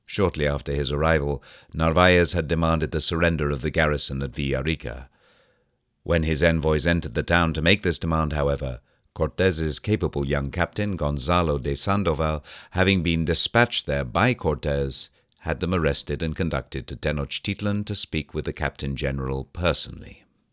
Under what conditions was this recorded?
The first was RMS Normalized, dynamically compressed and then limited; the second was the original raw recording. I have not tried de-essing yet. Same processing for the first; the second raw.